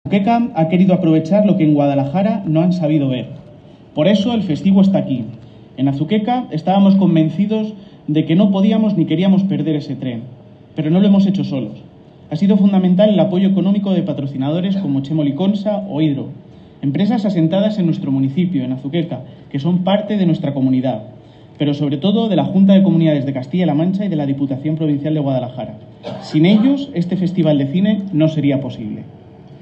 Declaraciones del alcalde, Miguel Óscar Aparicio 1
El alcalde, Miguel Óscar Aparicio, ha señalado en el discurso inaugural que el festival de cine ha permitido “reforzar nuestra apuesta cultural” y ha subrayado su componente “social y educativo”